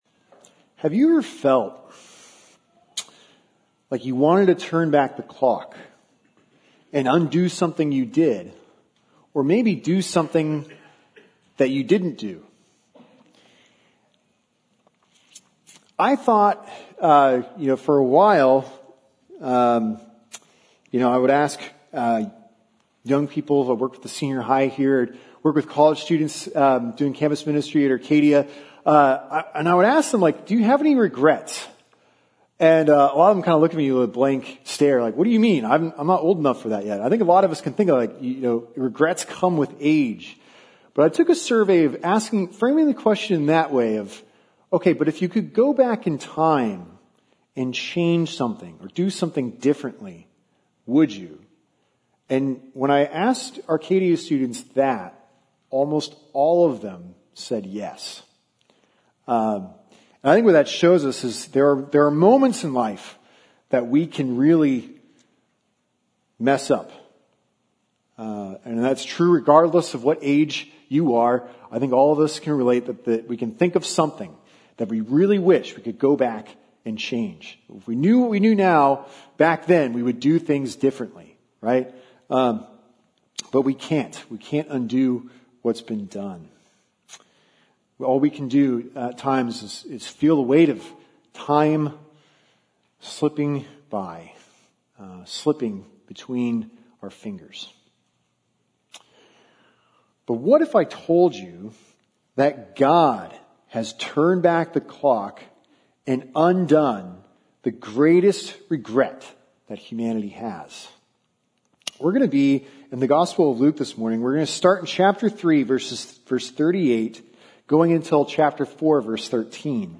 Sermons - New Life Glenside